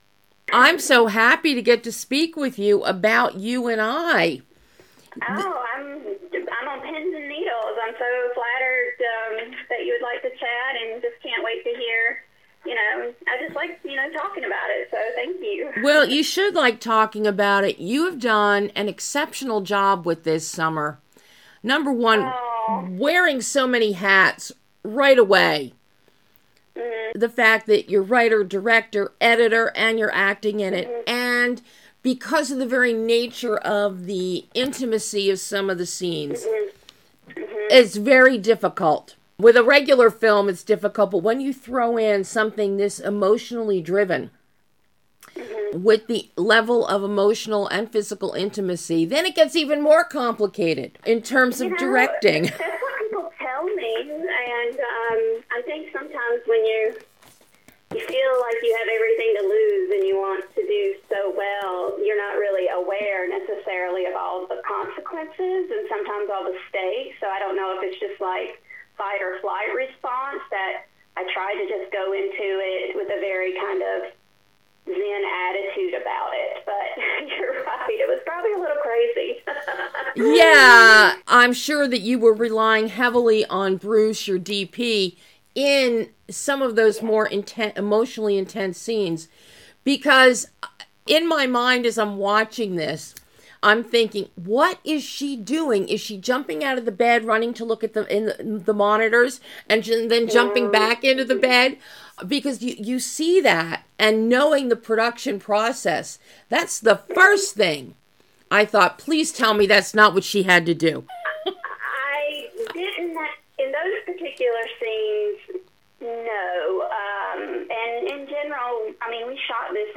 YOU & I - Exclusive Interview